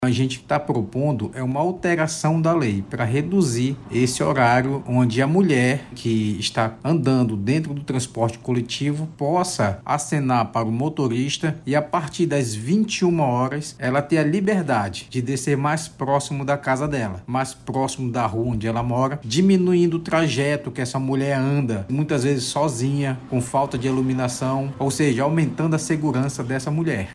O objetivo da medida é dar mais segurança ao público feminino e evitar exposição em locais onde há maior risco de violências contra a mulher, explica o autor do Projeto de Lei, o vereador Sérgio Baré, do PRD.